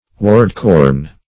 Search Result for " ward-corn" : The Collaborative International Dictionary of English v.0.48: Ward-corn \Ward"-corn`\, n. [Ward + F. corne horn, L. cornu.]